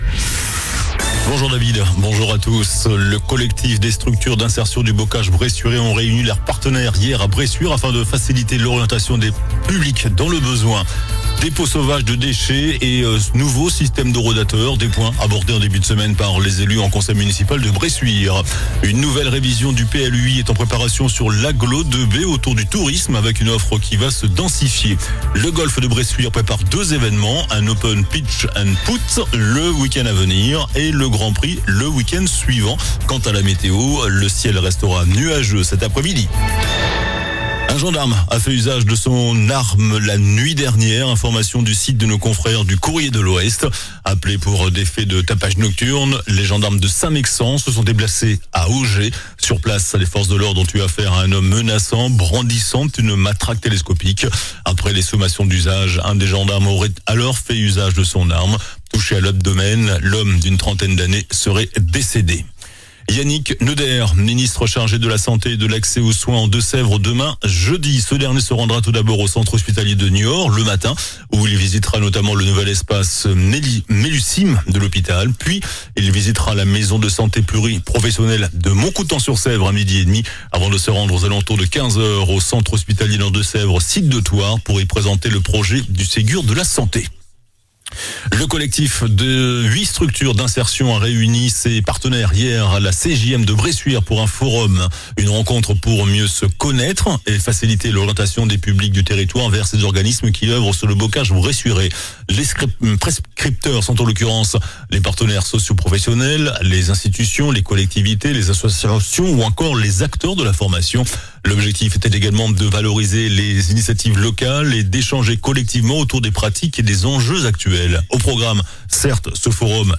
JOURNAL DU MERCREDI 21 MAI ( MIDI )